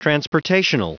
Prononciation du mot transportational en anglais (fichier audio)
Prononciation du mot : transportational